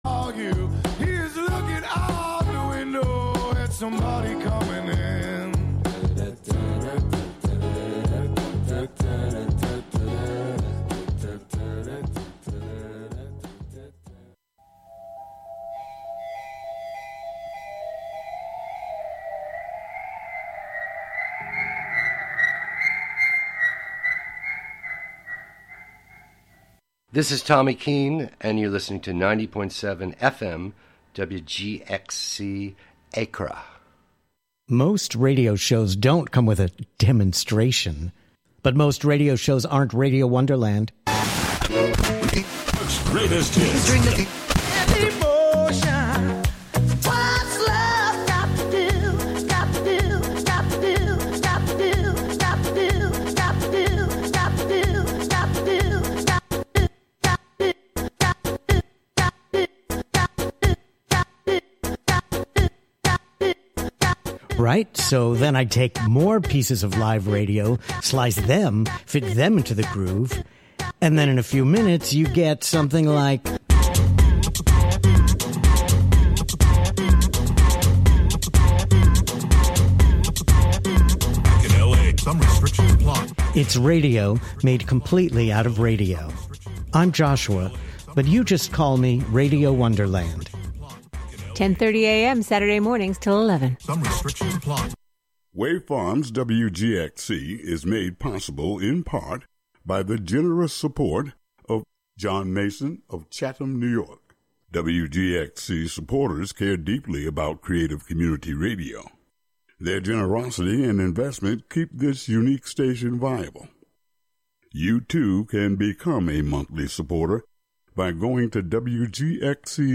Produced by WGXC.